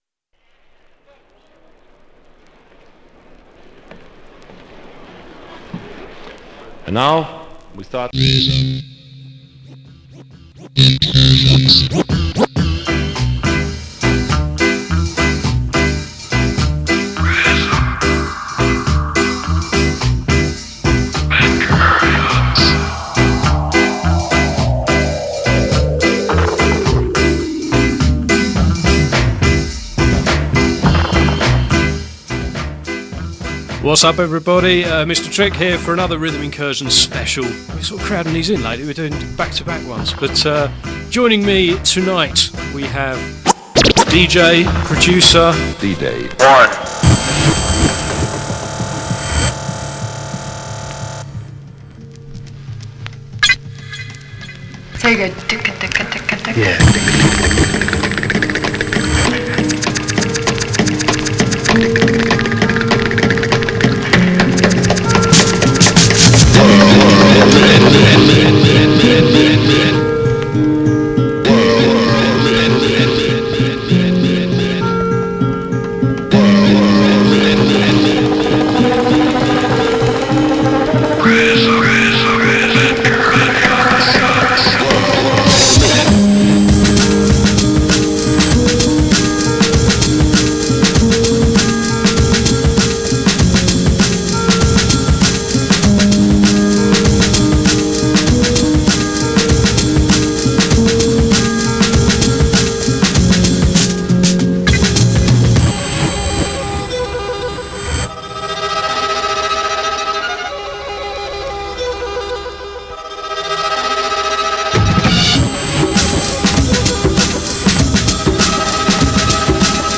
Real HipHop Mixes